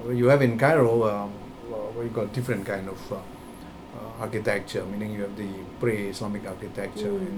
S1 = Taiwanese female S2 = Indonesian male Context: They are talking about places to visit in the Middle East.
The problem seems to be that islamic is spoken quite fast and not very loudly. Possibly, the emphasis on pre and lack of emphasis on islamic contributed to the difficulty. Note that S1 responded with the 'mm' backchannel even though she did not understand all the words.